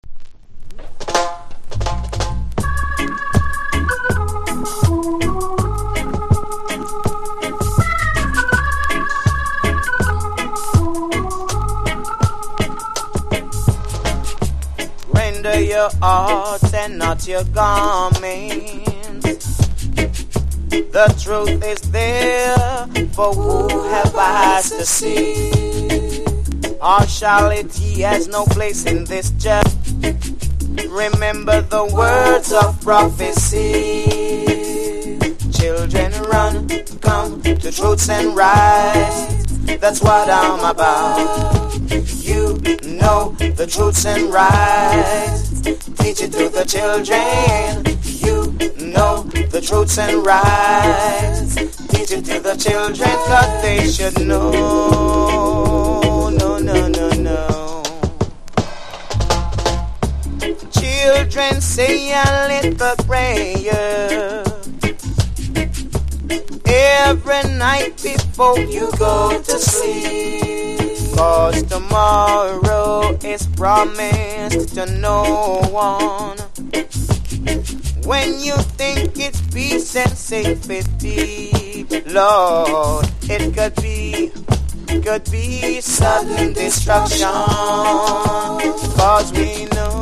• REGGAE-SKA
# ROOTS